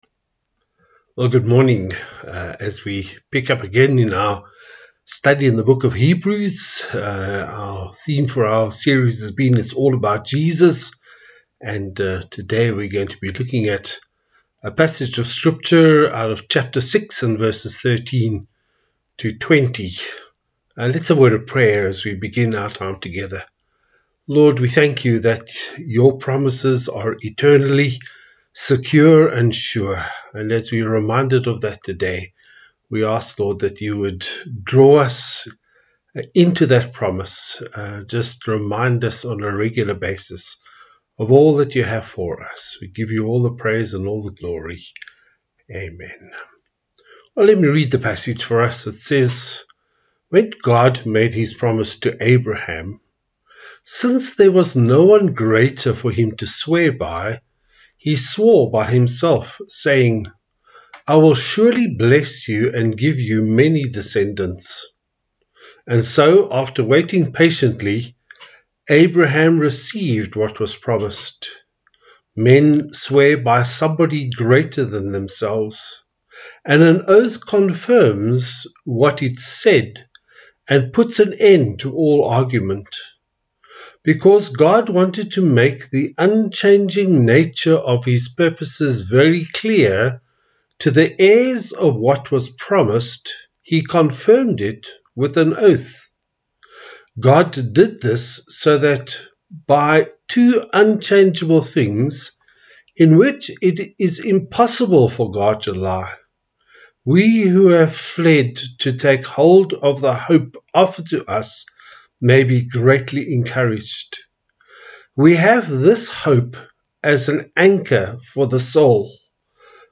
Passage: Hebrews 6:13-20 Service Type: Sunday Service